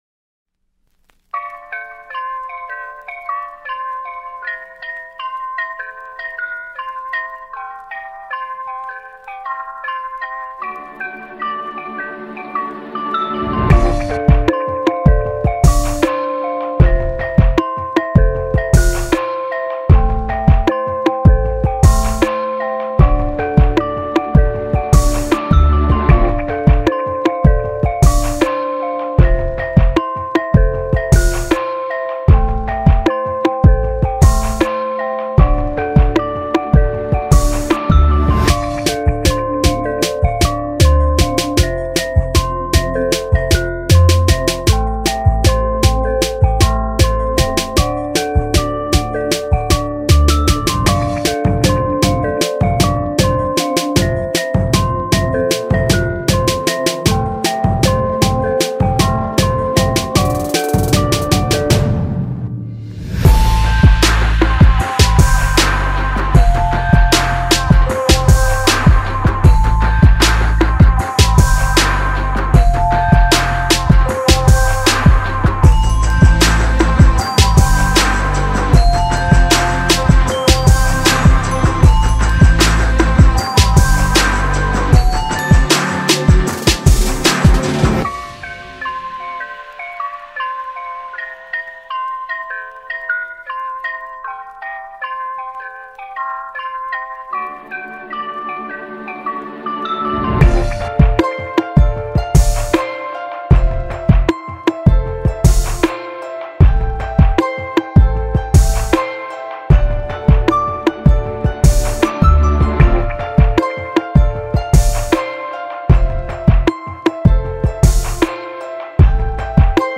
Instrumentais